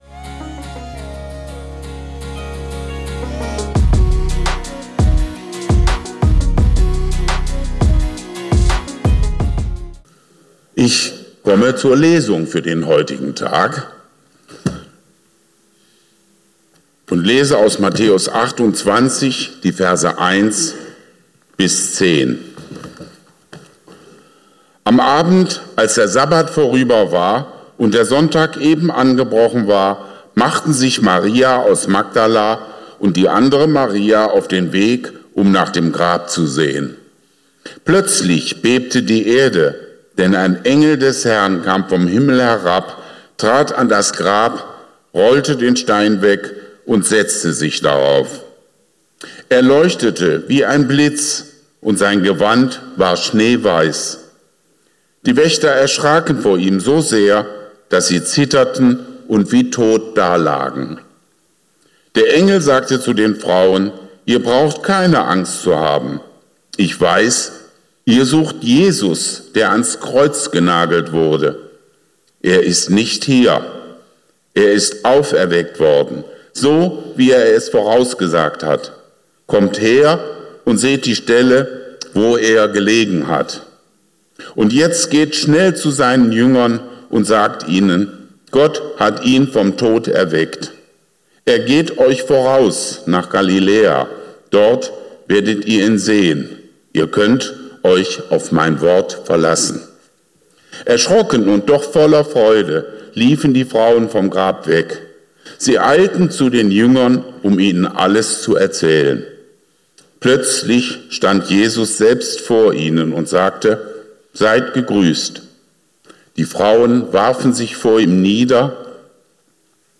Was Haben Deine SORGEN Mit OSTERN Zu Tun? ~ Geistliche Inputs, Andachten, Predigten Podcast